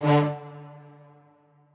strings1_37.ogg